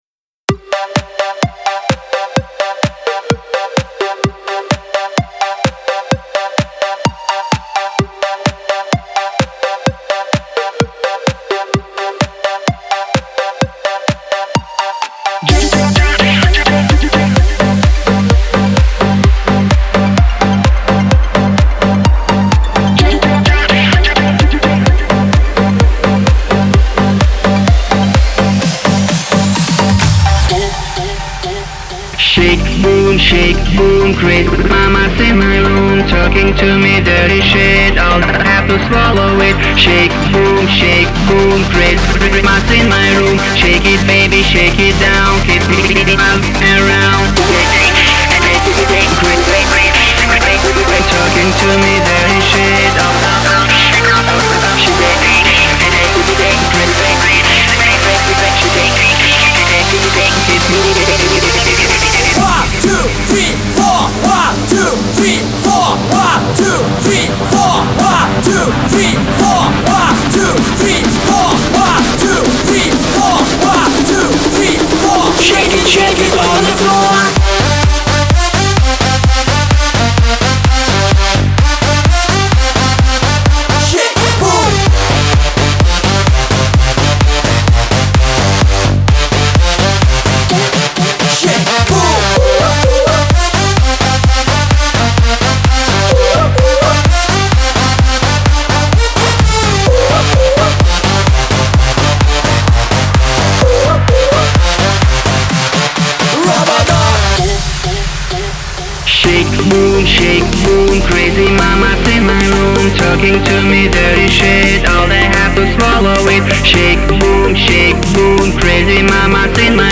Ein partytauglicher EDM Titel